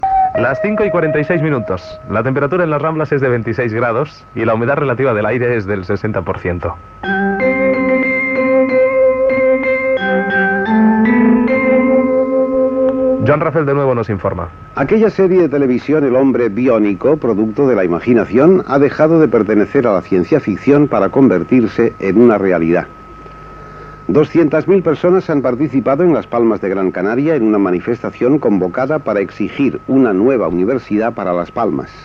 Hora, temperatura, sintonia, informatiu: l'home biònic, demanda d'una universitat per a Las Palmas.
Informatiu